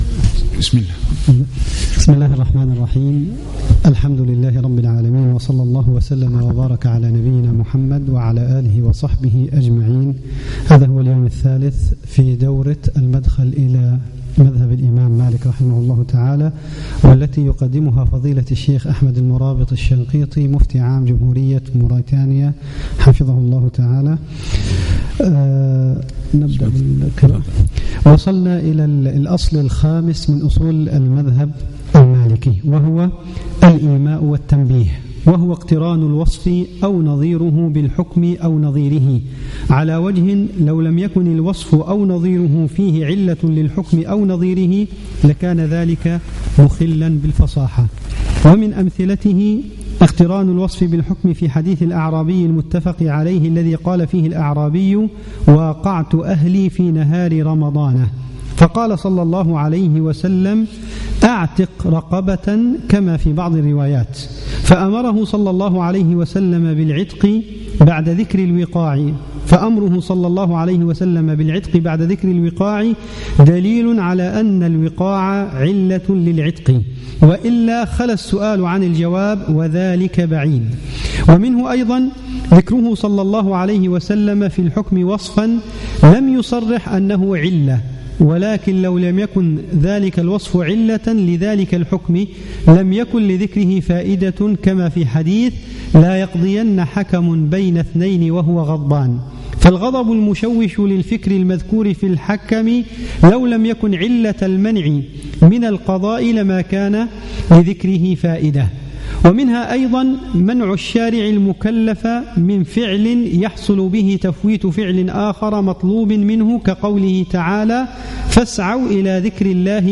صباح الأربعاء 22 جمادى الأولى 1437 الموافق 2 3 2016 بمبني تدريب الأئمة والمؤذنين
الدرس الخامس